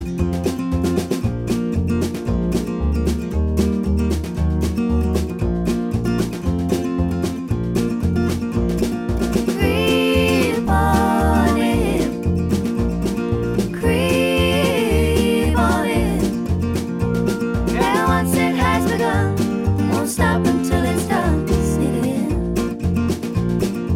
Duet Duets 2:58 Buy £1.50